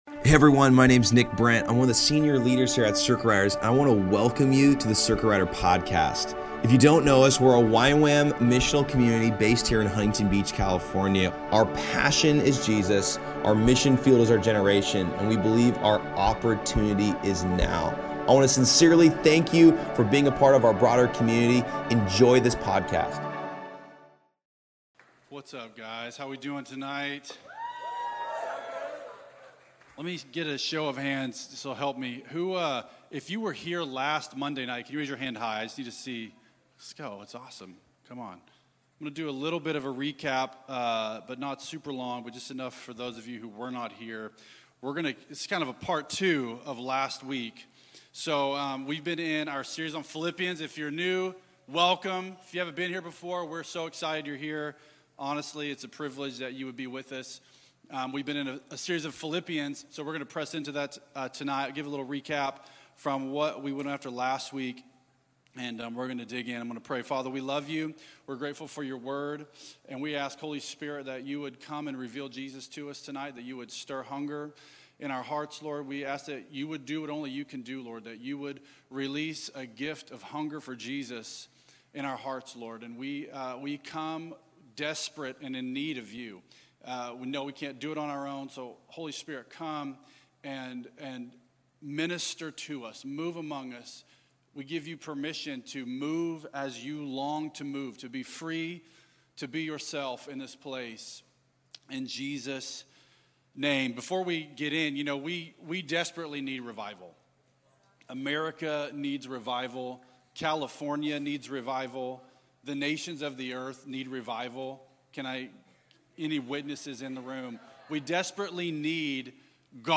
At Circuit Riders Monday Nights on May 13th 2025.
He ends the sermon by praying for an impartation of more hunger over everyone who wants it.